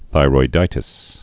(thīroi-dītĭs)